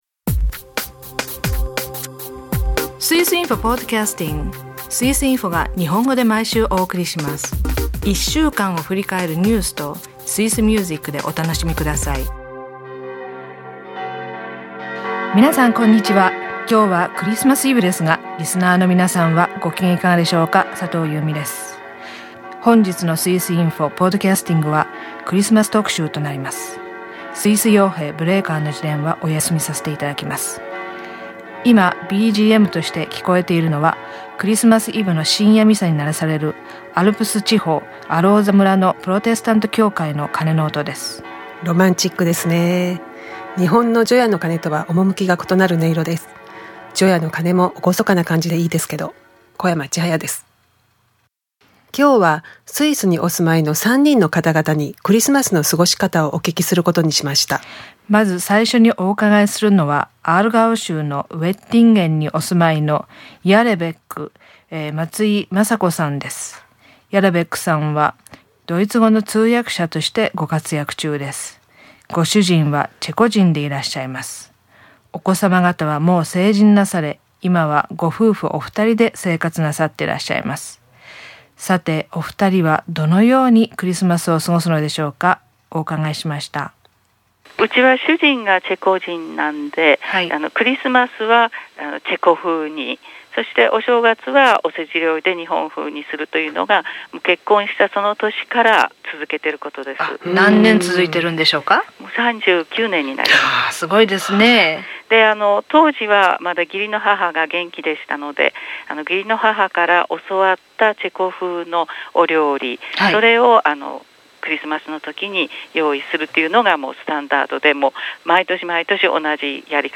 スイスにお住まいの日本の方々にクリスマスの過ごし方を聞きました。